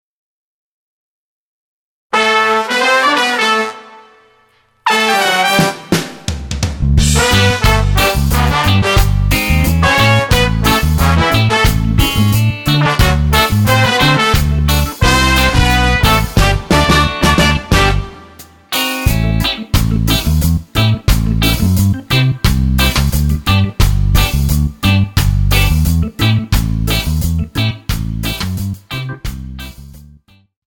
Tonart:G ohne Chor